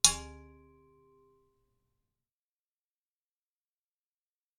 sword_4
field-recording metal metallic ping sword sound effect free sound royalty free Sound Effects